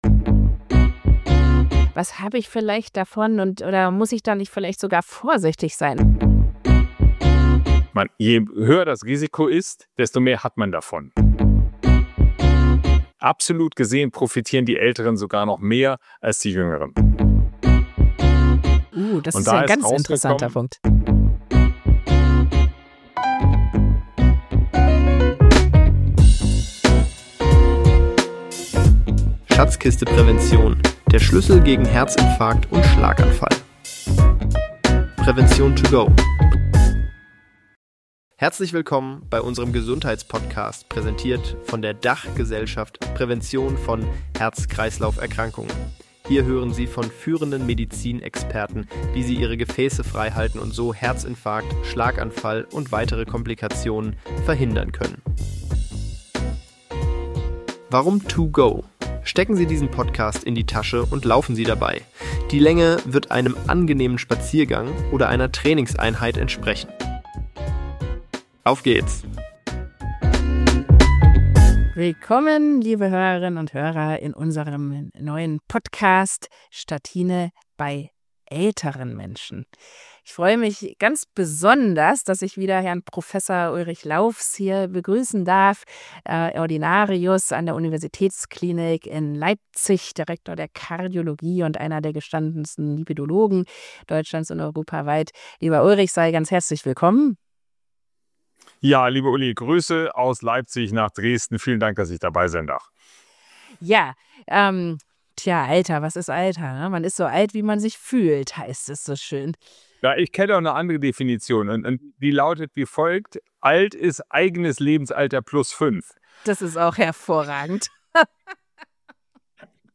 Ist das mit 70 oder auch 80 Jahren wirklich noch notwendig? Im Gespräch